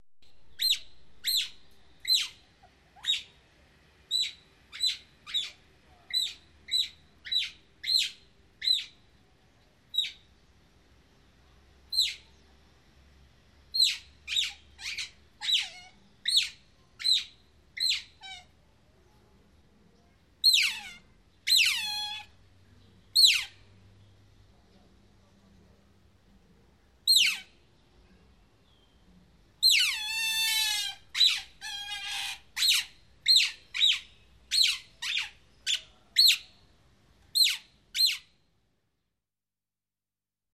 Звуки выдры
Восточная бескоготная выдра издает такой звук